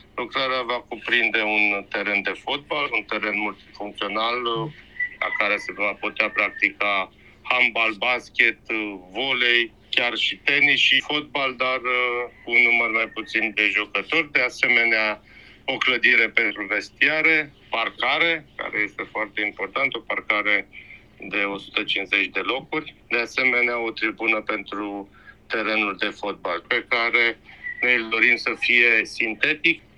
Mai multe ne spune primarul orașului Râșnov, Liviu Butnariu: